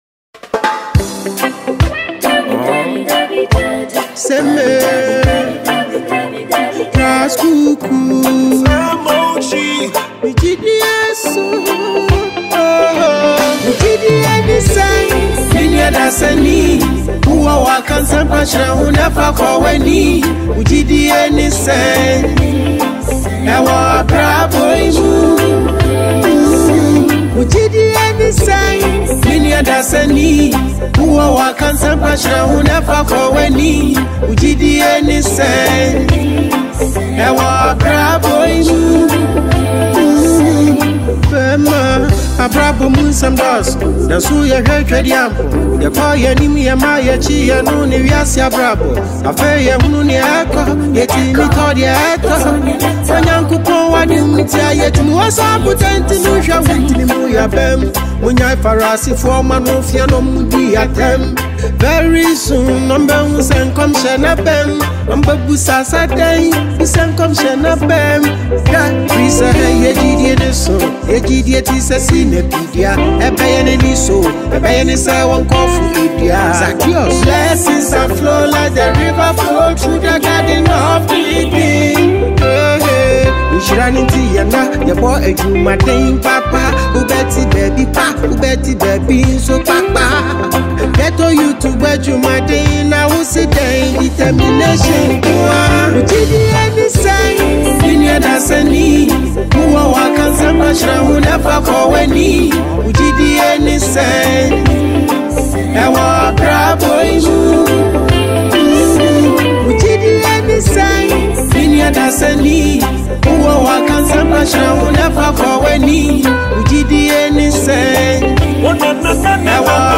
Ghana MusicMusic
reggae gospel